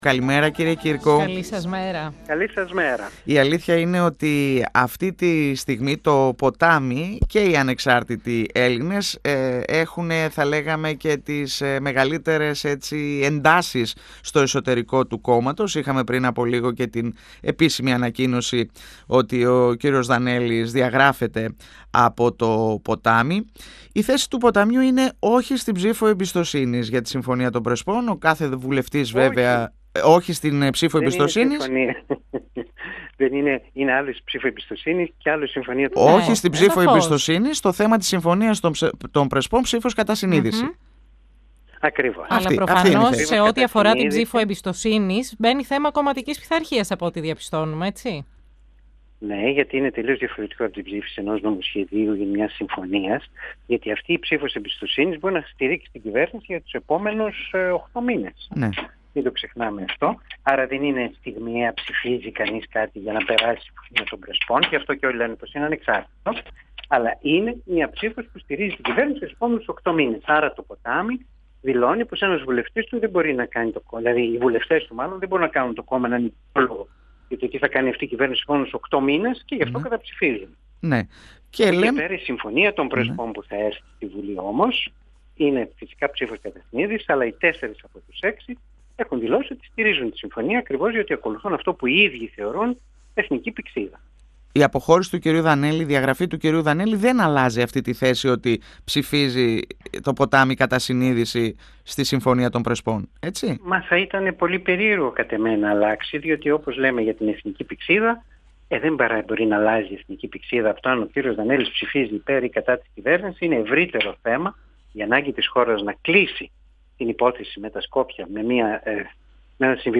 Την ανάγκη να κλείσει η υπόθεση της ΠΓΔΜ με έναν πολύ θετικό συμβιβασμό –όπως χαρακτήρισε τη Συμφωνία των Πρεσπών- επισήμανε μιλώντας 102 fm της ΕΡΤ3 ο ευρωβουλευτής του Ποταμιού Μιλτιάδης Κύρκος.